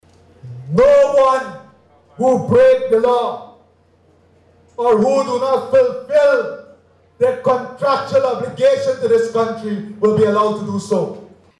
This declaration came during his speech at a memorial event for the late former President Dr. Cheddi Jagan at Babu Jaan over the weekend.